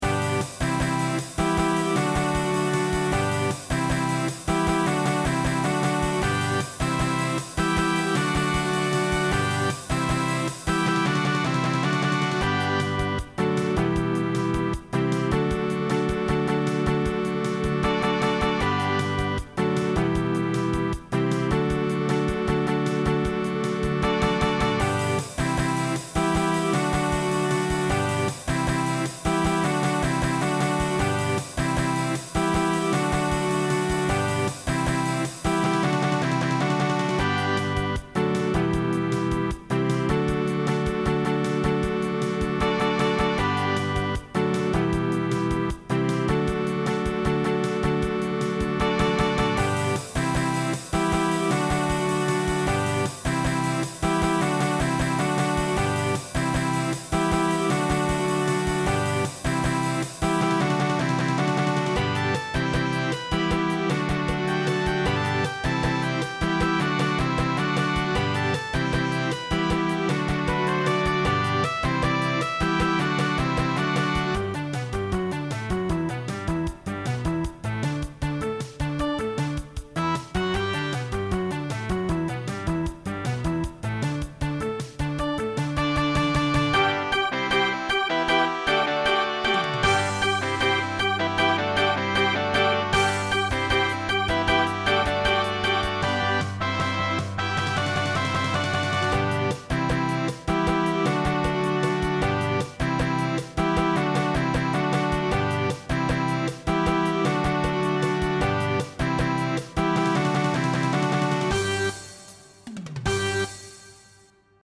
Voicing SSA Instrumental combo Genre Rock